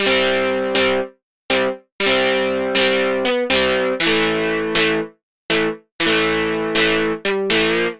synth guitar
Aj na subtractore sa da nagenerovat umela gitara pri trochu snazeni sa, hoc to znie furt velmi umelo a velmi nie verne...ale nic lepsie synteticke som nepocul :-)
synth_guitar_dist_01.mp3